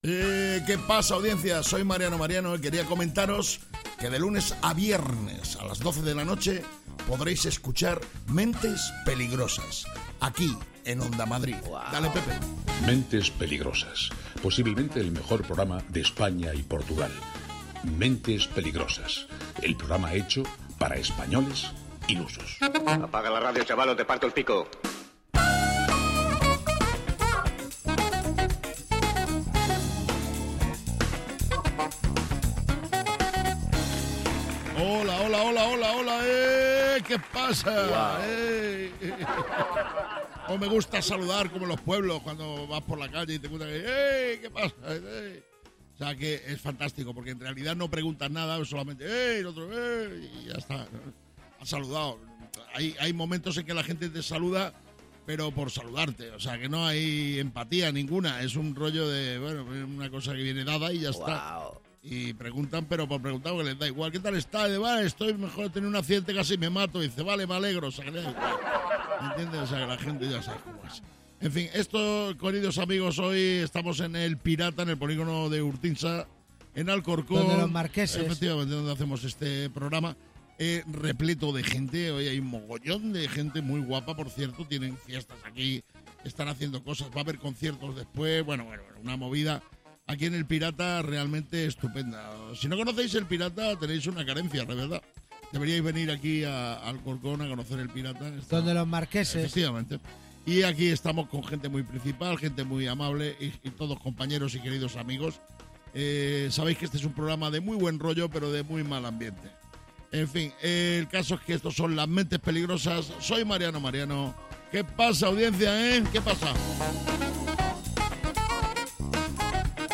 ¿Es un programa de humor?